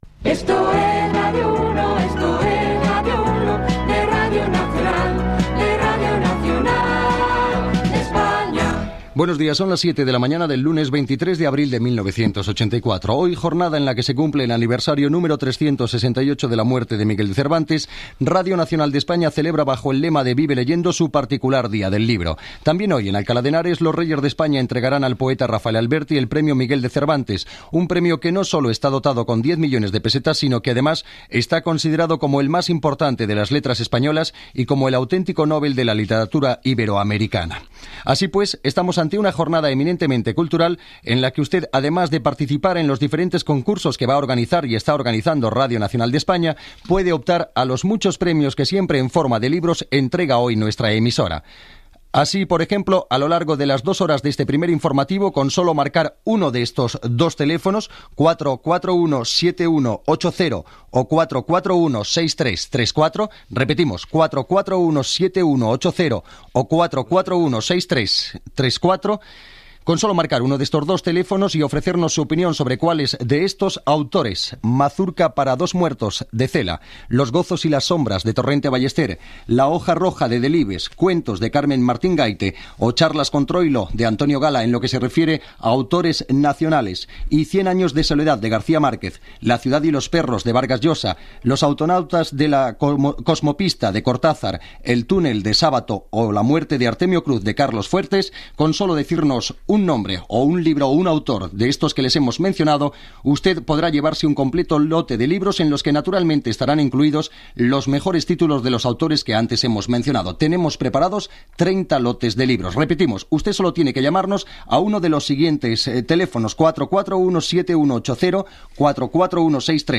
Amb motiu del lliurament del premio Cervantes, entrevista al poeta Rafael Alberti. Setmana de la poesia a Huelva.
Gènere radiofònic Informatiu